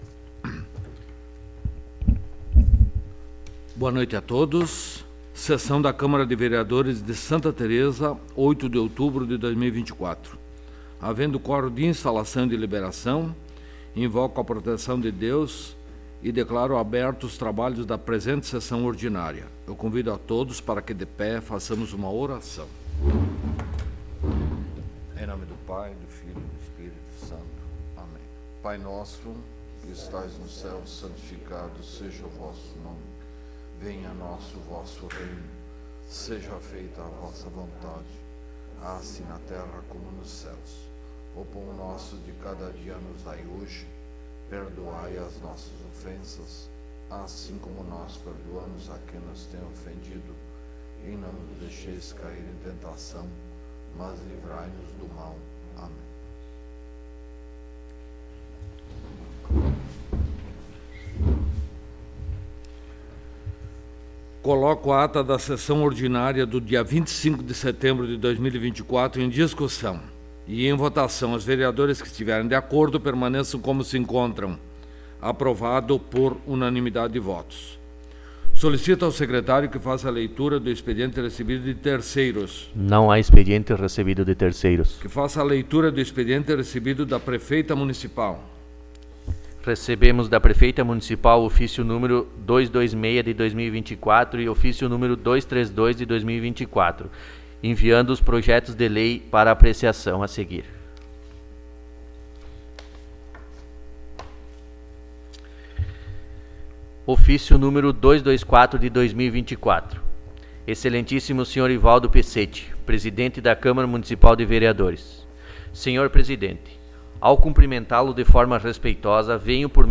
17ª Sessão Ordinária de 2024
Áudio da Sessão